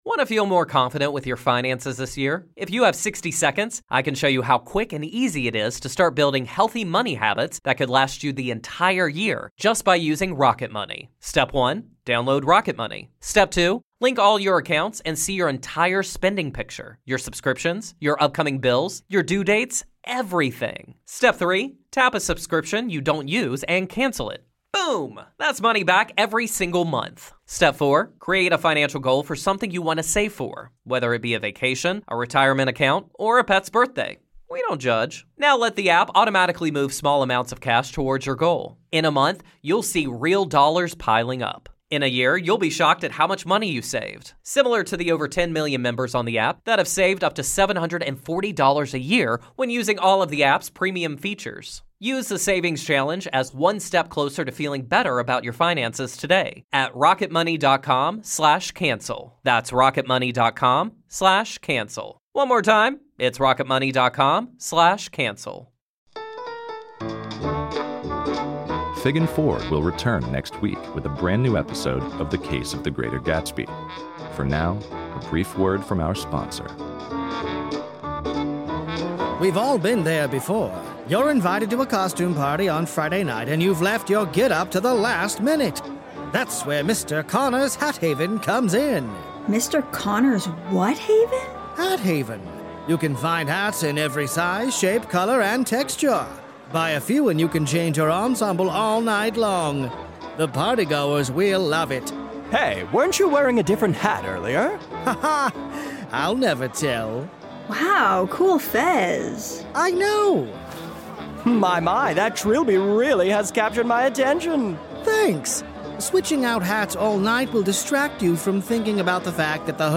Fig & Ford will be back with a brand new episode next week, but in the meantime, here's a word from one of our sponsors.